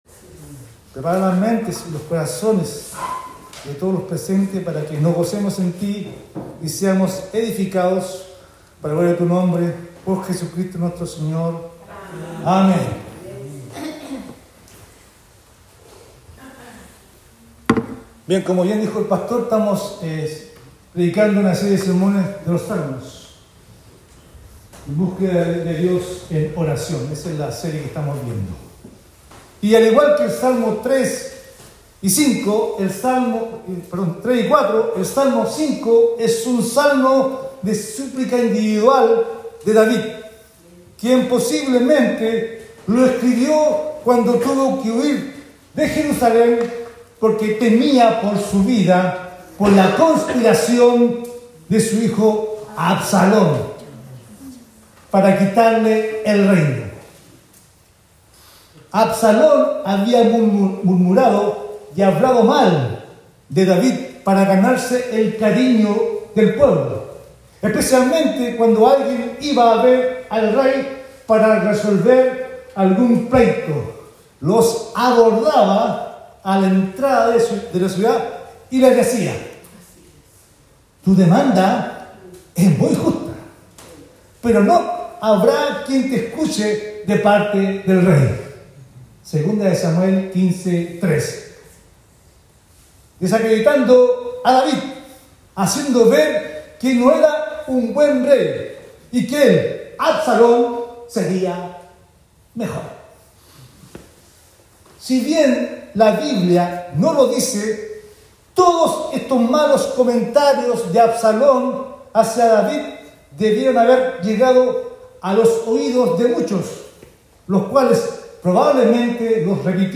Sermones
Website de la Iglesia St. James de Punta Arenas Chile